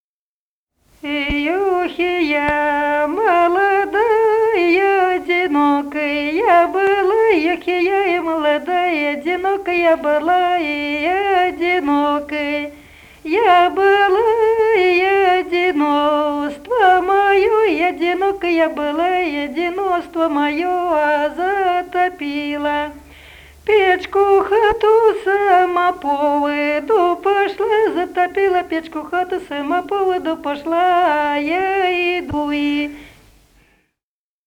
Народные песни Смоленской области
«И ох, и я молода» (плясовая).